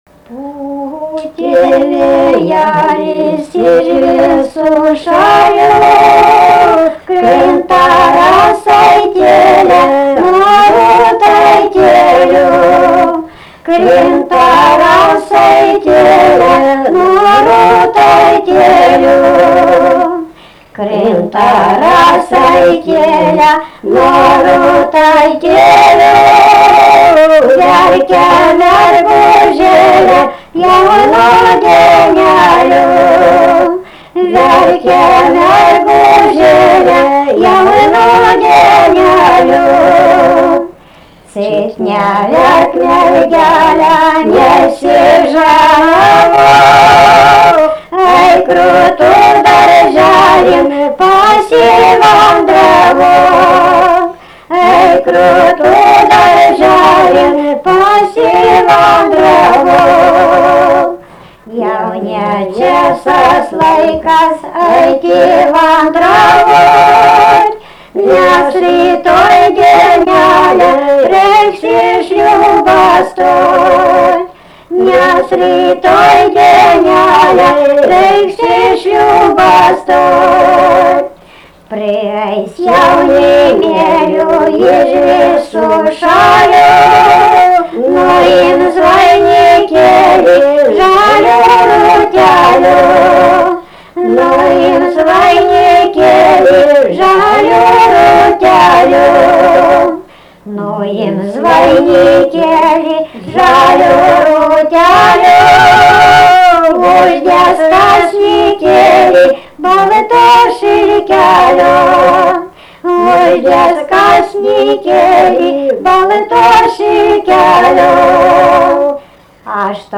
Tameliai
vokalinis